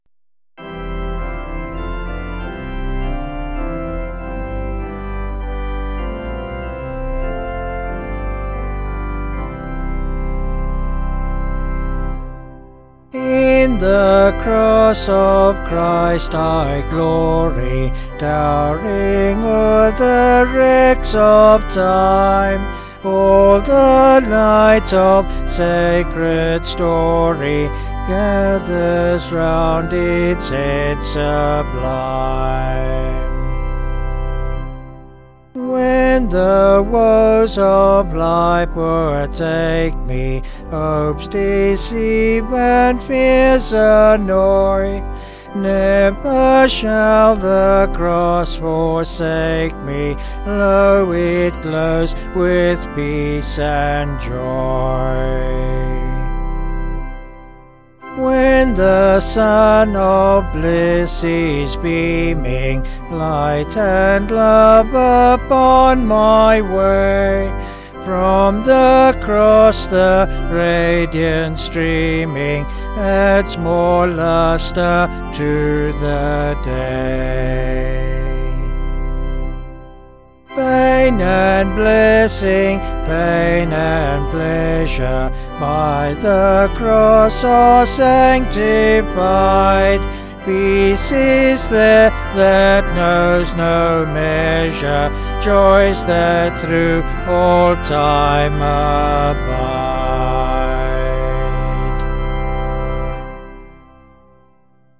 Vocals and Organ   706.3kb Sung Lyrics